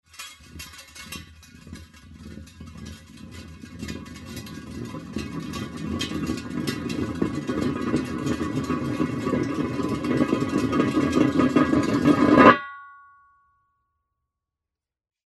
Звуки шиномонтажа